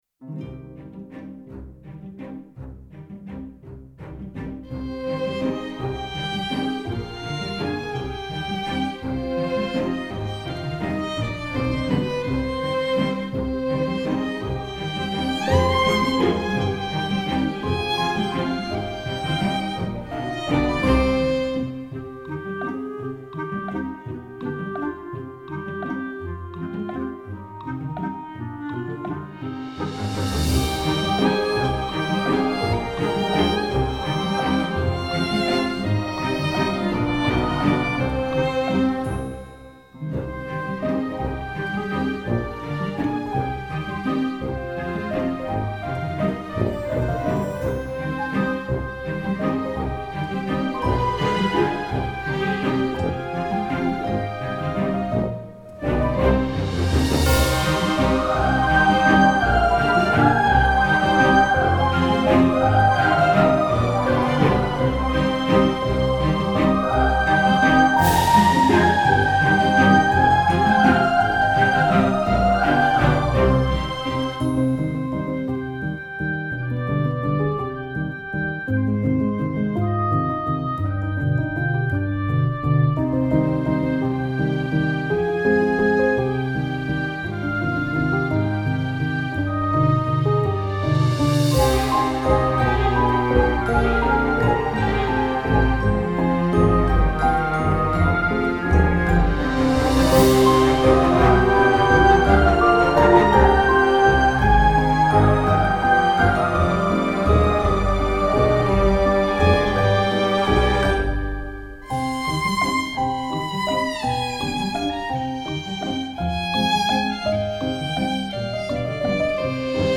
Мои успехи в оркестровке(2).
Мож, не по теме вопроса, но я бы седьмую ступень использовал натуральную, не повышенную.